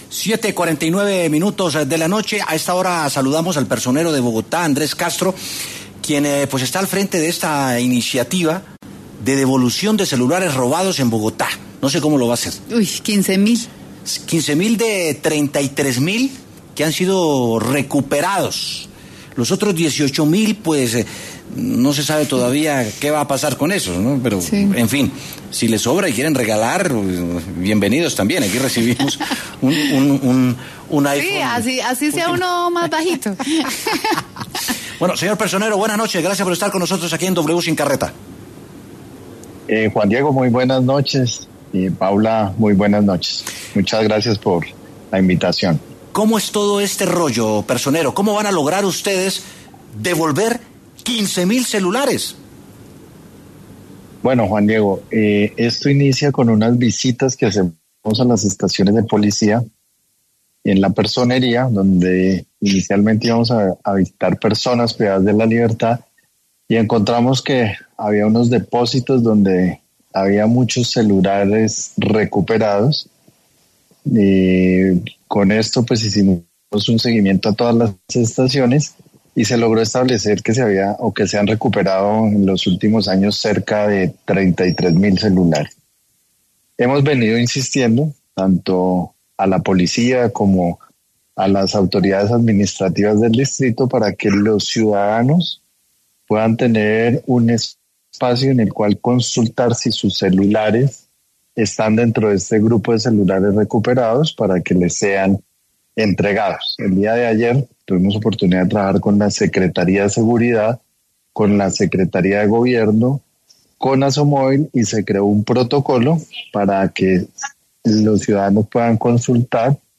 El personero Andrés Castro pasó por los micrófonos de W Sin Carreta para hablar sobre el tema.